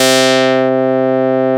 SYNTH CLAV.wav